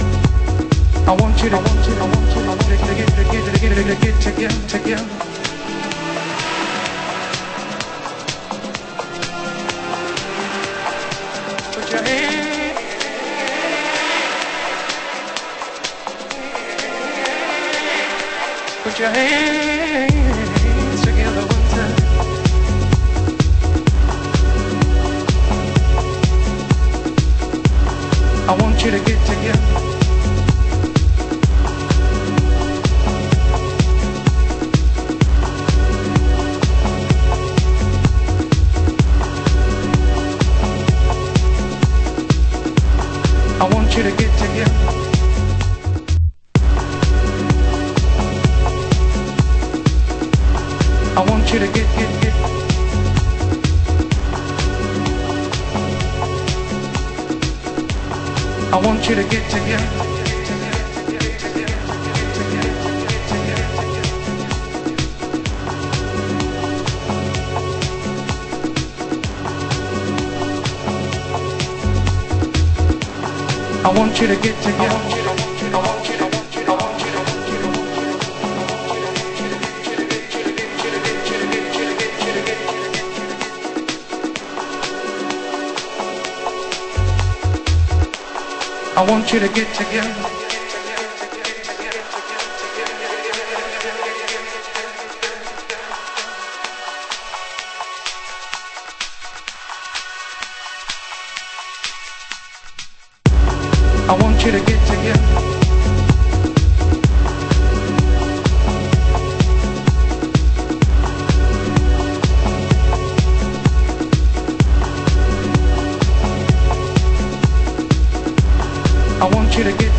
盤質：イントロ等に少しチリノイズ有/ラベルにマーキングシール有